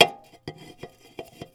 household
Coffee Mug Set on Table